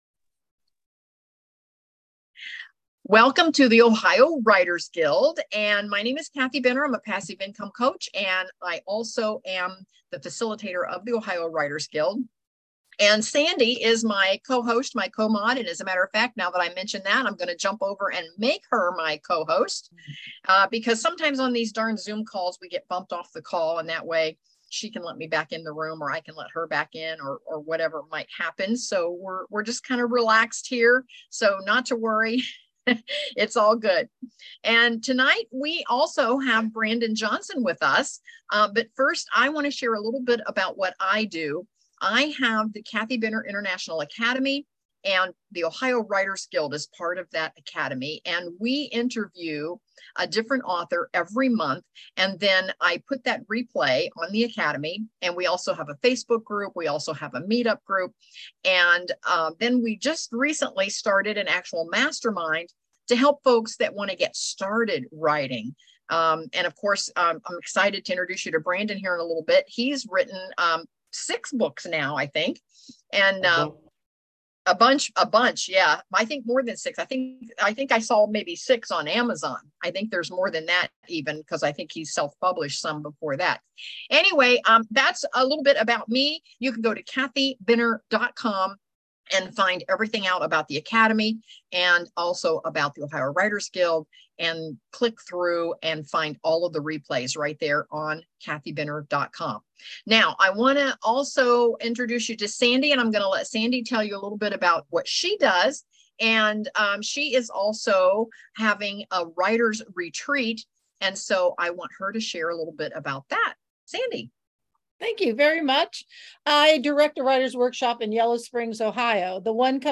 July 11, 2023, Ohio Writers' Guild | Interview